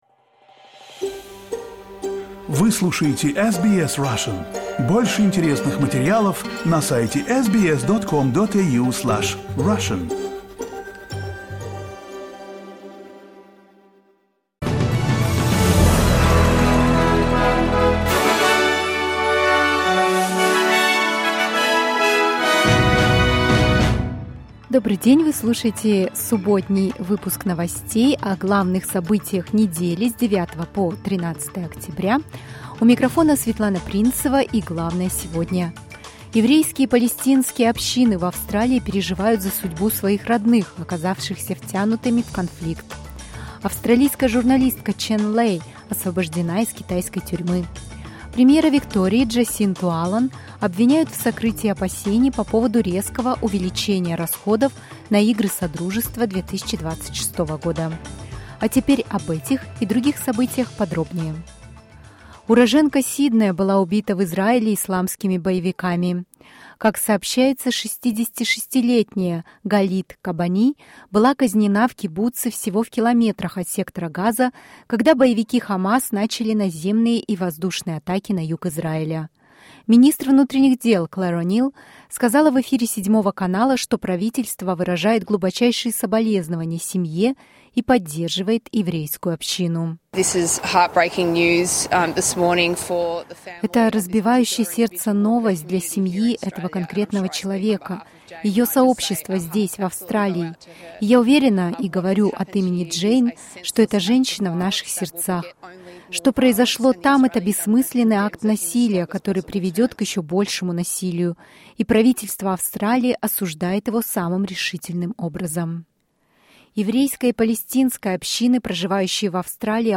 Weekly news wrap in Russian - 14.10.2023